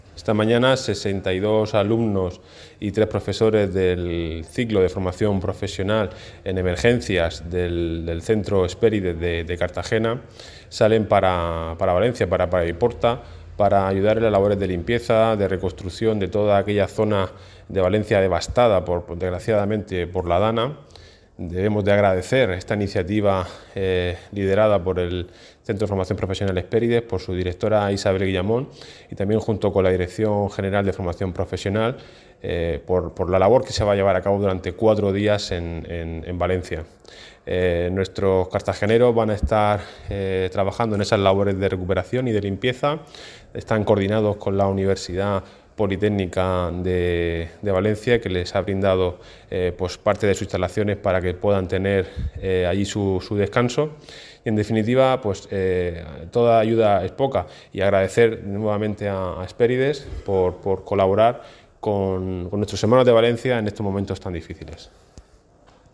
Enlace a Declaraciones del concejal de Empleo, Álvaro Valdés